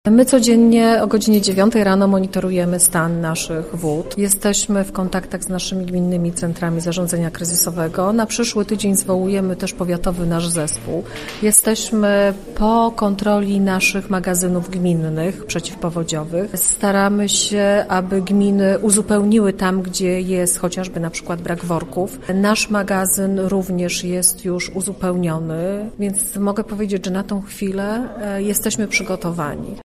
Do ewentualnych sytuacji zagrożeń jest też przygotowany powiat. Mówi starosta gorzowski Małgorzata Domagała. https